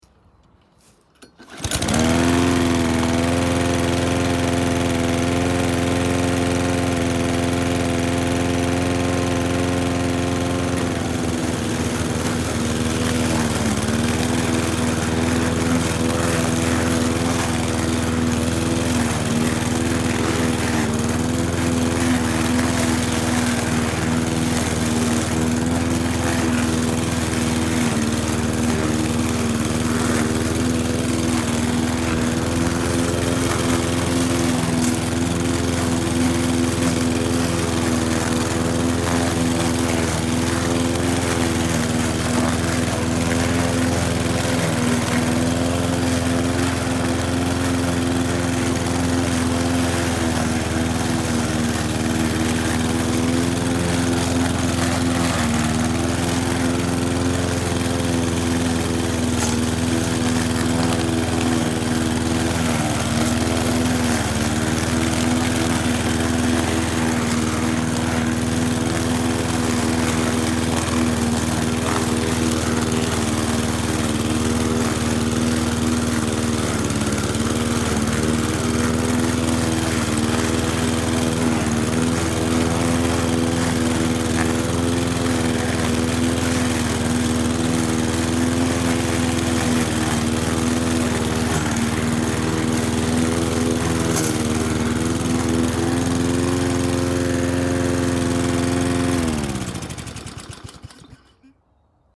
Ниже вы можете прослушать, а если очень надо, то и загрузить бесплатно звуки кошения травы бензиновой газонокосилкой.
Звук, где заводят бензиновую газонокосилку с помощью шворки (ручной запуск), косят газон, шум работающей мотокосы, и глушат двигатель в конце